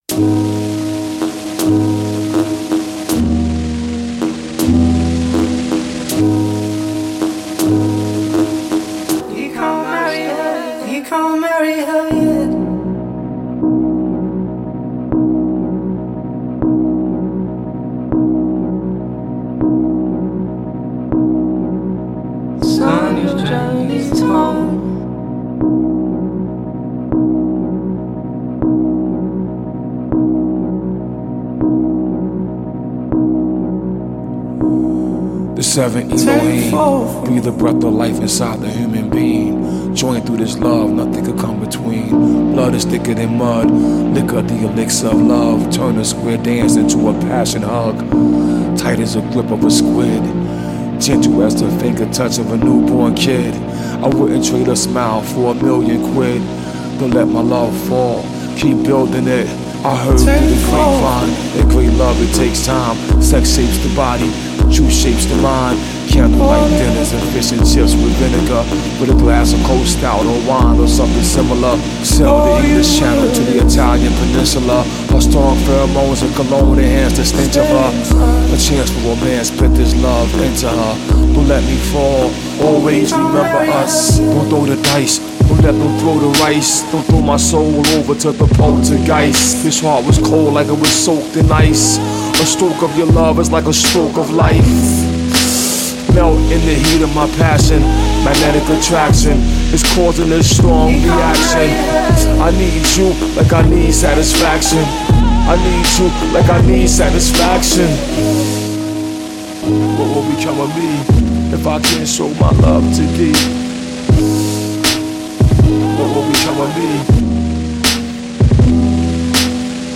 موسیقی الکترونیک
موسیقی هیپ هاپ